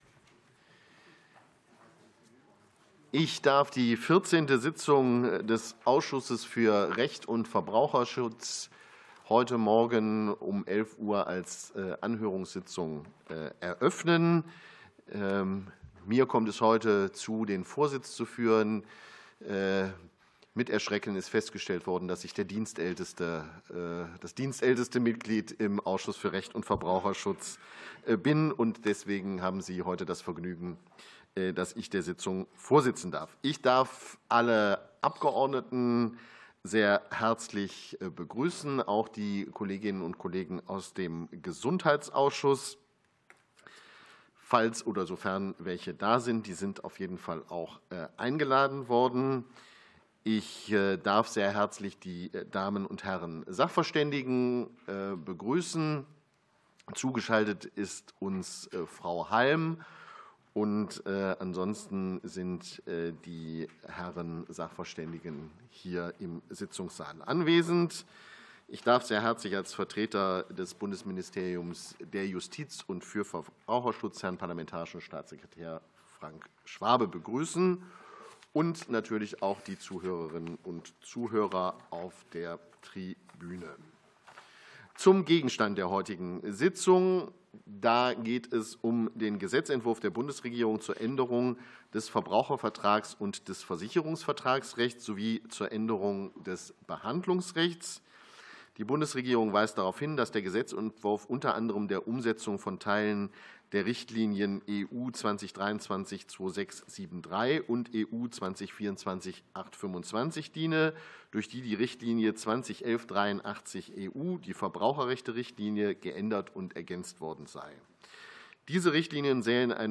Anhörung des Ausschusses für Recht und Verbraucherschutz